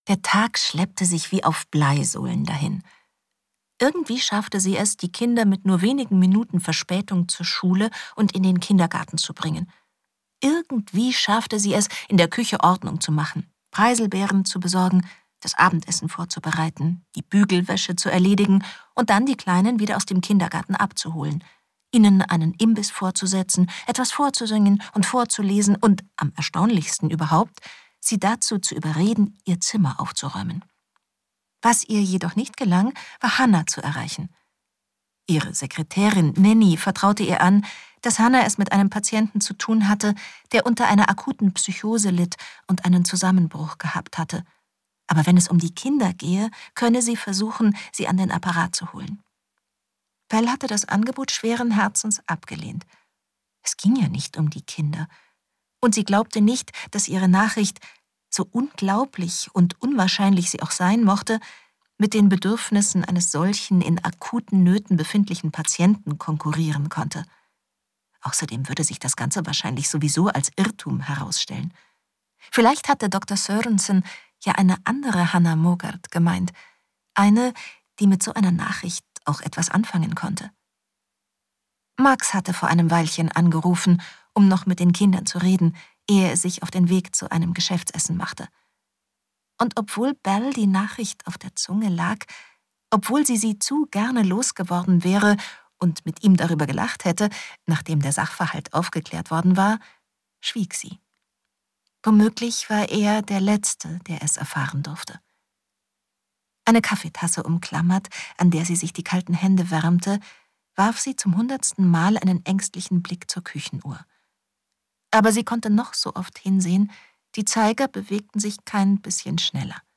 Die Fotografin – Am Anfang des Weges (mp3-Hörbuch)